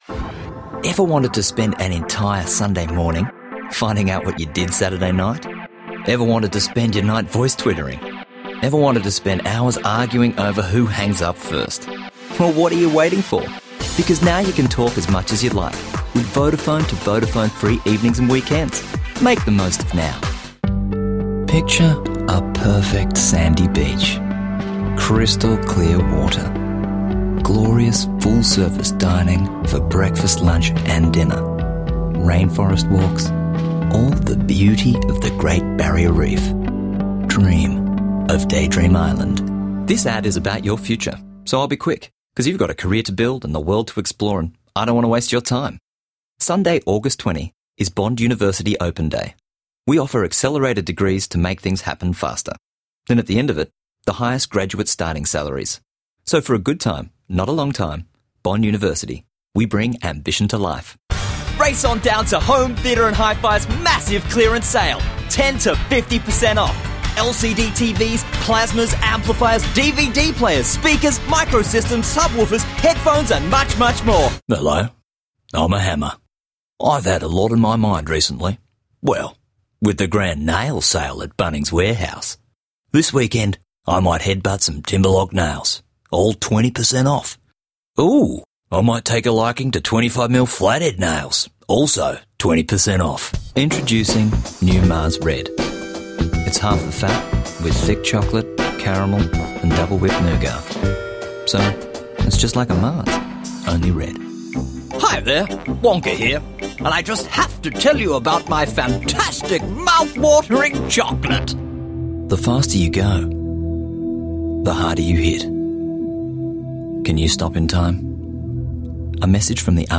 Commercial Compilation